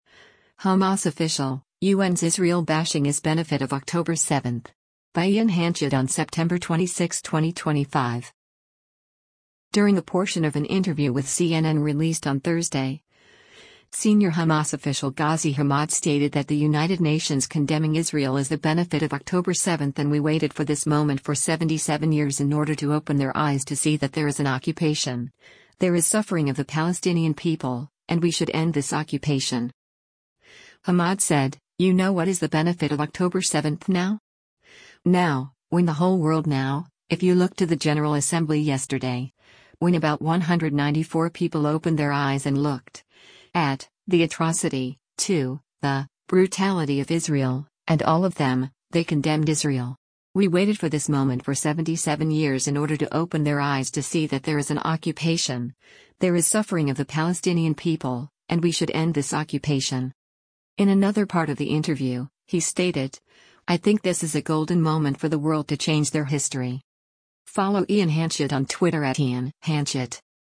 During a portion of an interview with CNN released on Thursday, senior Hamas official Ghazi Hamad stated that the United Nations condemning Israel is “the benefit of October 7” and “We waited for this moment for 77 years in order to open their eyes to see that there is an occupation, there is suffering of the Palestinian people, and we should end this occupation.”